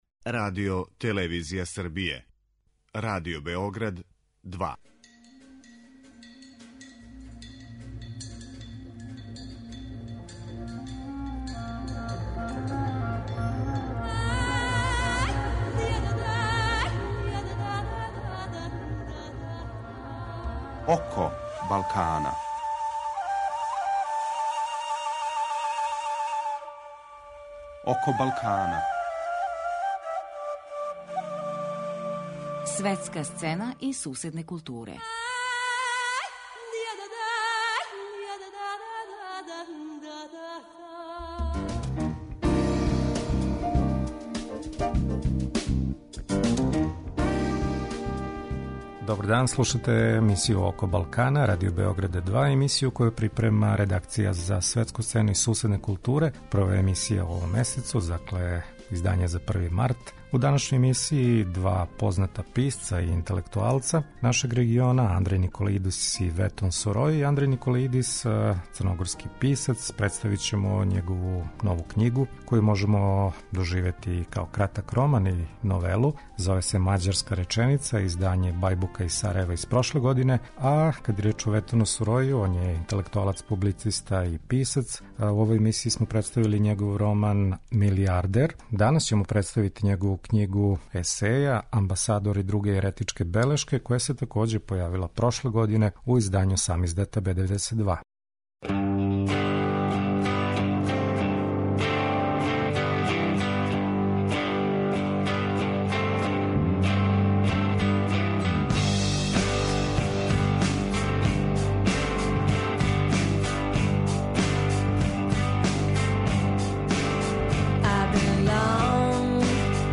Први саговорник нам је Андреј Николаидис, један од најбољих црногорских писаца средње генерације.
Други саговорник у емисији је познати косовски интелектуалац, писац и публициста Ветон Сурои.